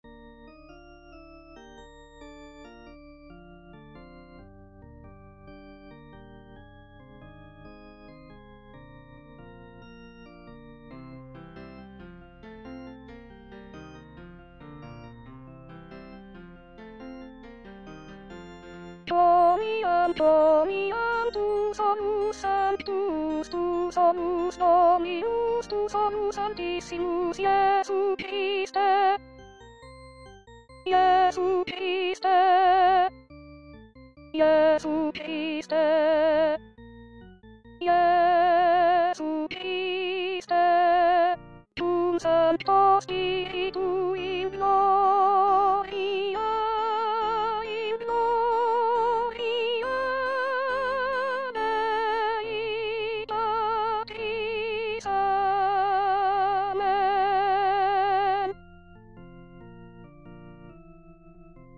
Chanté:     S1